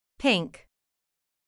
音の響きを鼻で感じながら、鼻から息を抜くように発音します。